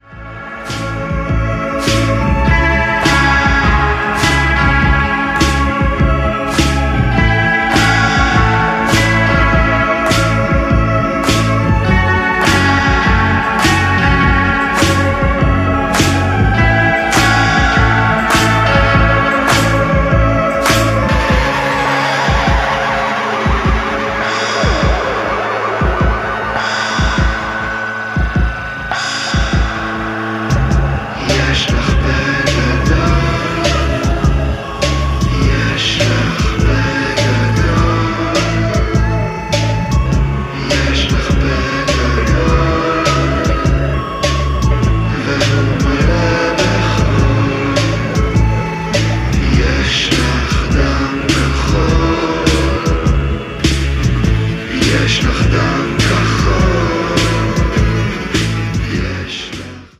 Electronix